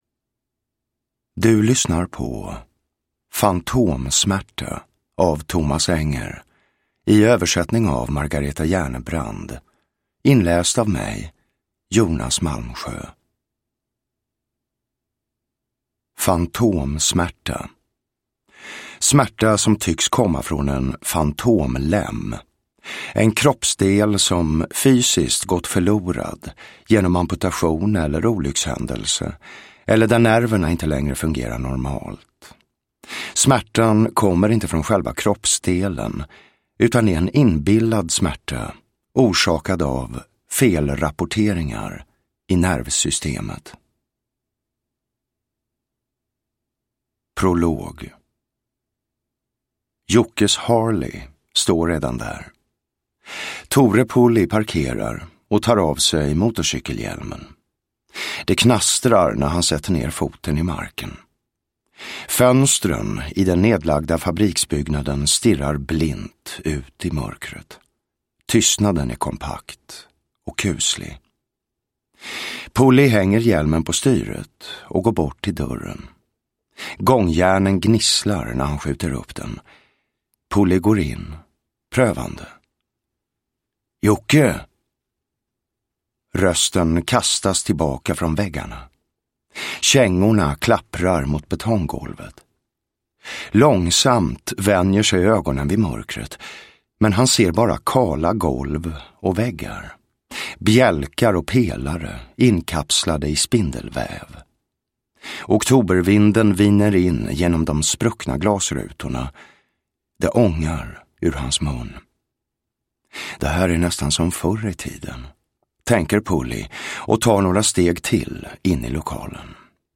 Fantomsmärta – Ljudbok – Laddas ner
Uppläsare: Jonas Malmsjö